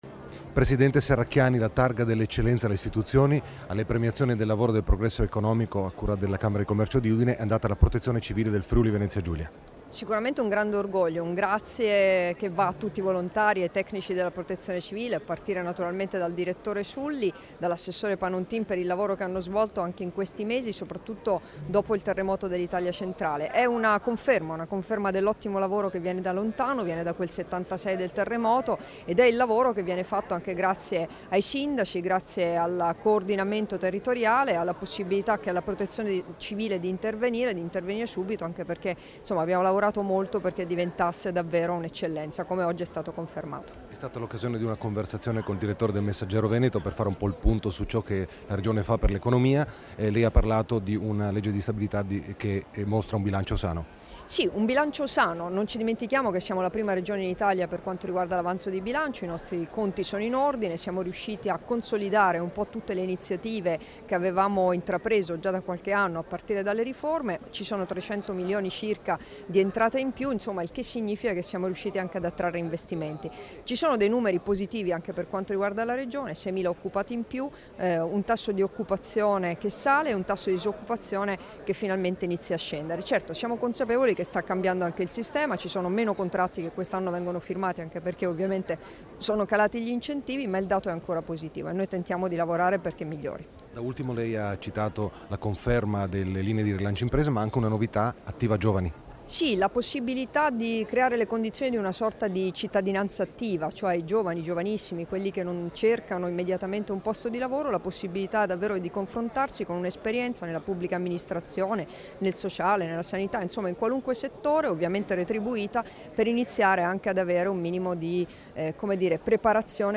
Dichiarazioni di Debora Serracchiani (Formato MP3) [1983KB]
a margine della cerimonia di assegnazione della Targa dell'eccellenza alla Protezione civile del Friuli Venezia Giulia nel corso delle Premiazioni del lavoro e del progresso economico rilasciate a Udine il 16 novembre 2016